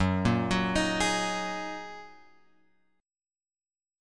F# 6th Add 9th